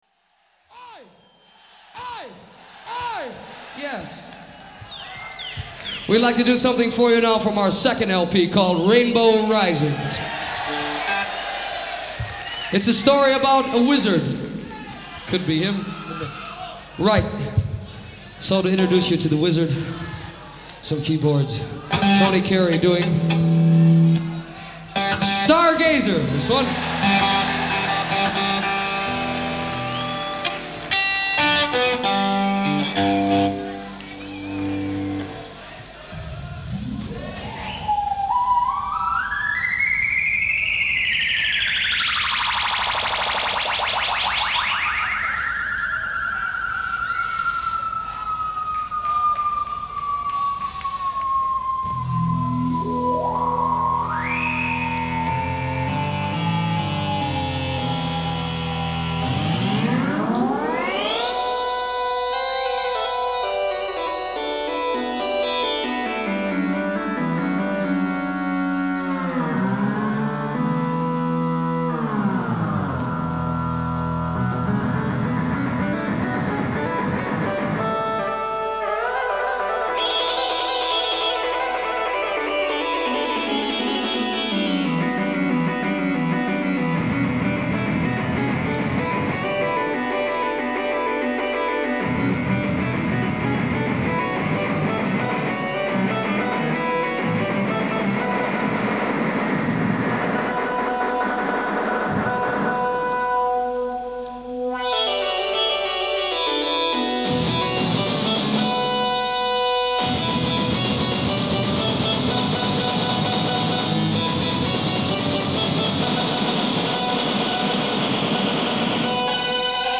концертный альбом